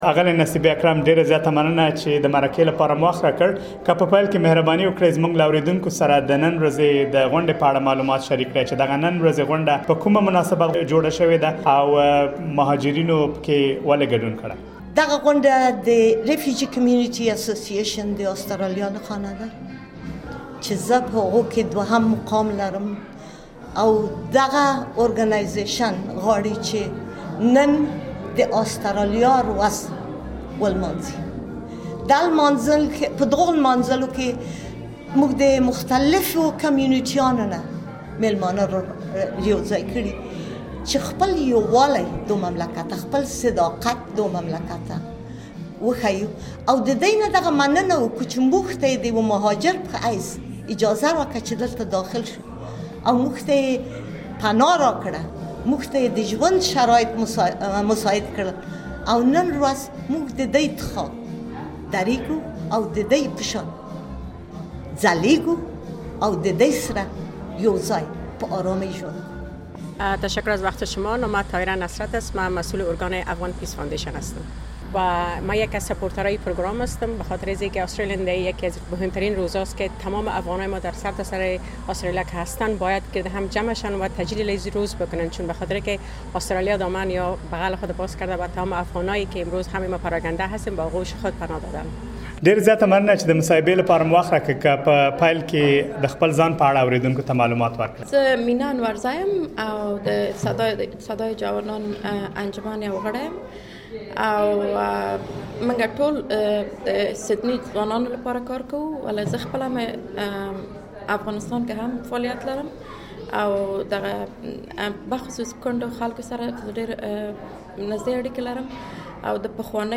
د آسټرالیا په سیډني ښار کې د یو شمېر نورو ټولنو د غړو ترڅنګ یو ډله افغانانو هم د آسټرالیا ورځ ولمانځله. اس بي اس پښتو د آسټرالیا د ورځې په مناسبت د ترسره شوې غونډې له یو شمېر ګډونوالو سره مرکې ترسره کړي.